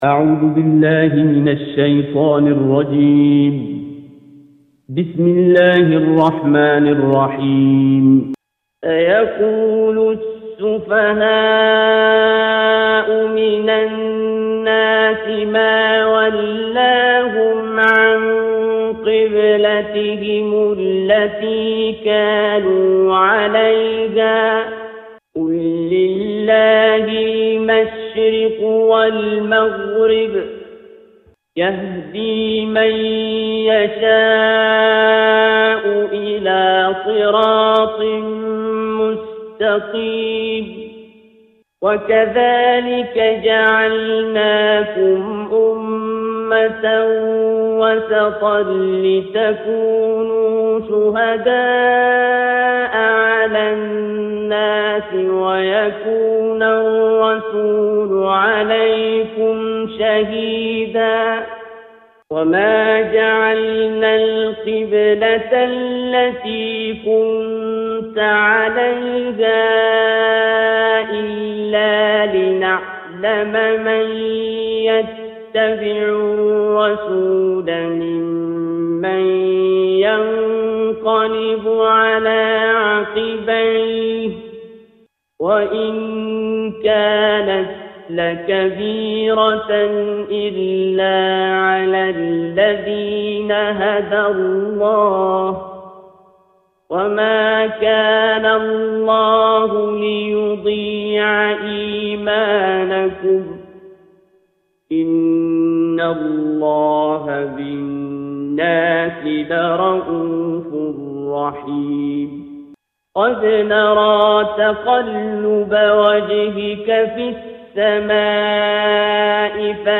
Surah Al-Baqarah - A Lecture of Tafseer ul Quran Al-Bayan by Javed Ahmed Ghamidi.